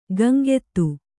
♪ Gaŋgettu